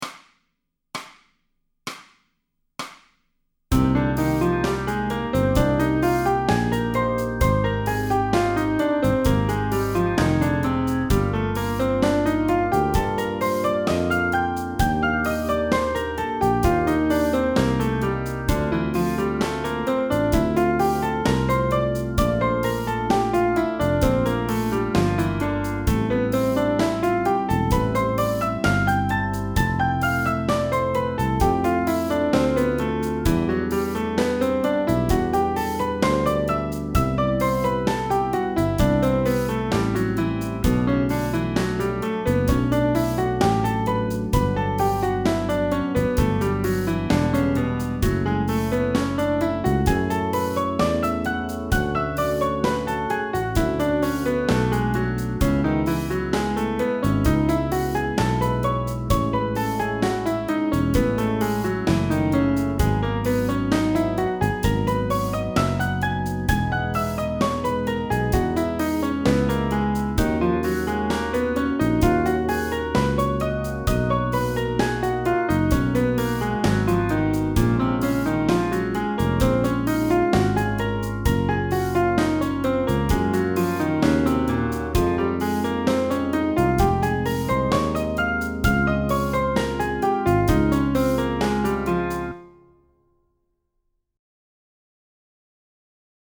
Etude pour Piano